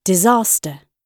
Disaster /dɪˈzɑːstə(r)/
disaster__gb_2.mp3